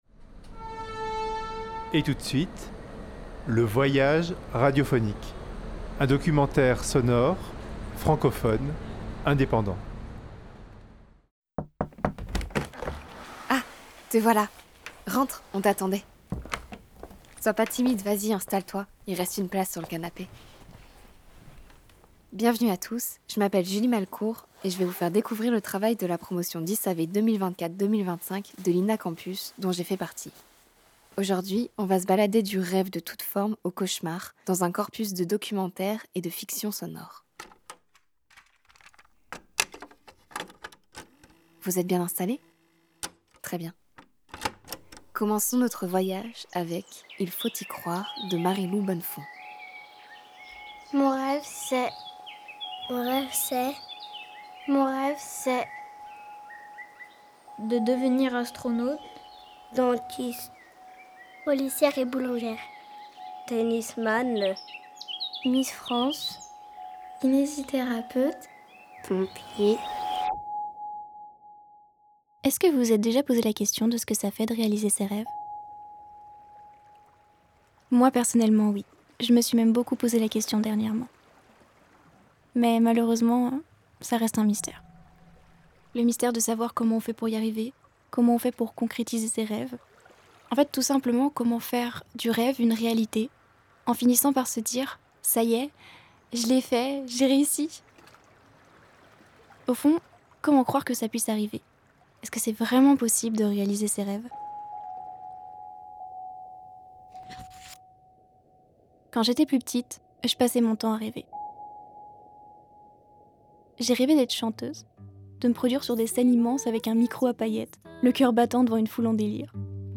Vous voilà entré dans le salon sonore du rêve. Chaque année, dans le cadre de leurs études, les étudiants du DIS Audiovisuel 2024-2025 de l’INA Campus ont l’occasion de réaliser un ou deux projets de création sonore, de l’idée à la production finale.
RÊVONS Une revue sonore collective France – 2025 Vous voilà entré dans le salon sonore du rêve.